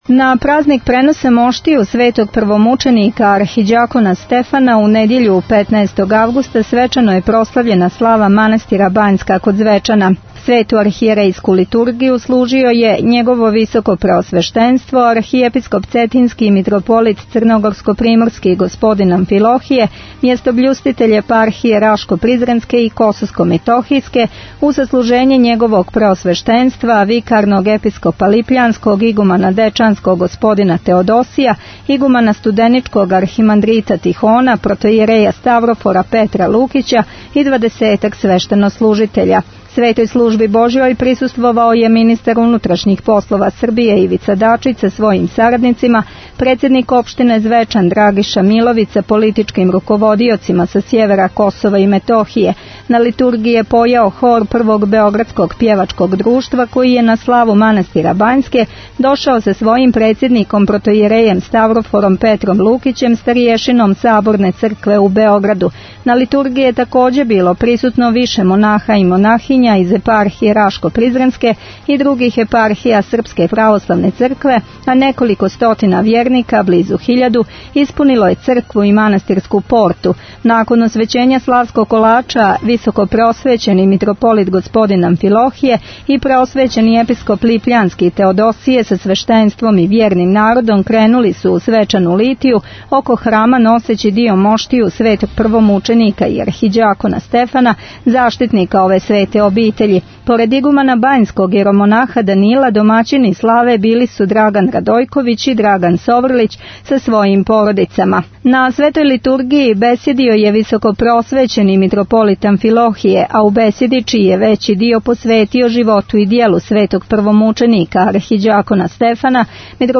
Download the file . 25:41 минута (4.41 МБ) На празник преноса моштију Св. Првомученика Архиђакона Стефана свечано је прослављена слава манастира Бањске код Звечана.
На Литургији је пјевао хор Првог београдског пјевачког друштва.